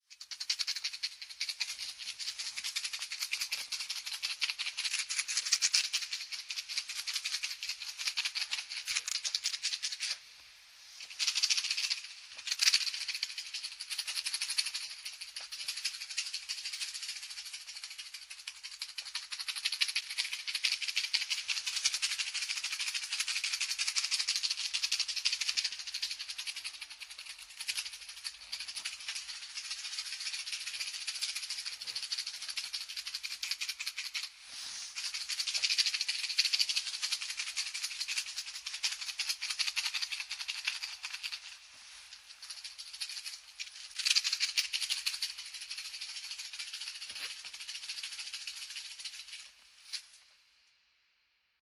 バイノーラル録音(立体音響) マッチ しゃかしゃか